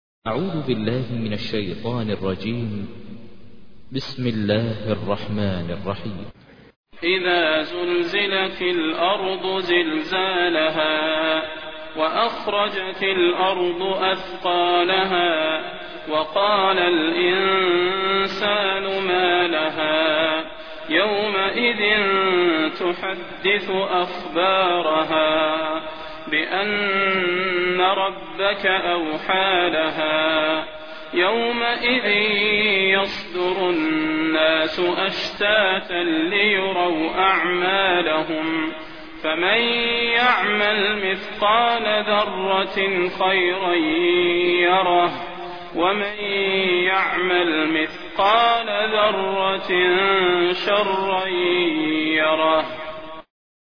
تحميل : 99. سورة الزلزلة / القارئ ماهر المعيقلي / القرآن الكريم / موقع يا حسين